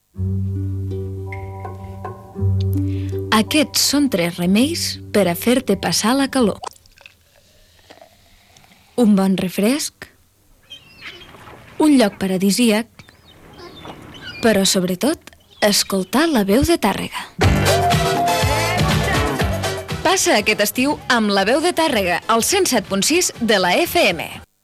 Indicatiu d'estiu de l'emissora